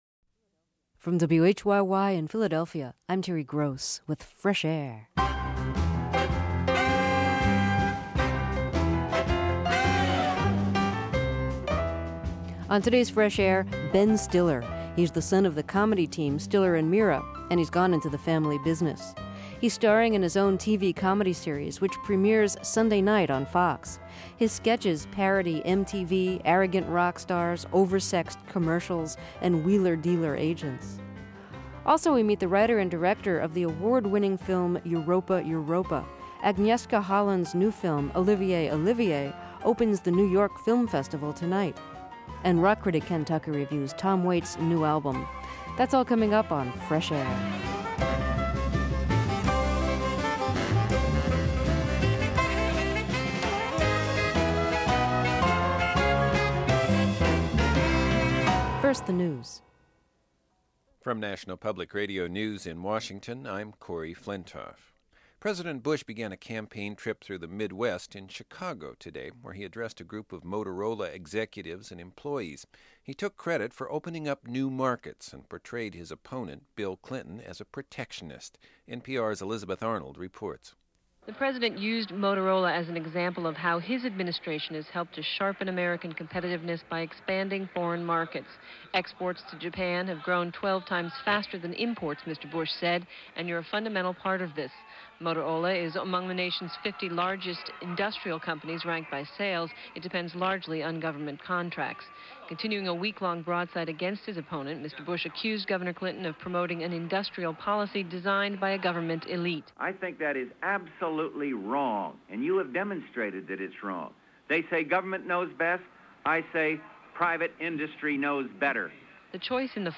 MTV's new Thursday-night sketch-comedy series Human Giant got its start as a collection of Web shorts, and the rise of MySpace and the viral-video explosion helped it develop a big following online. Terry talks with Aziz Ansari, Rob Heubel and Paul Scheer, three of the comics who power the show's alterna-comedy antics.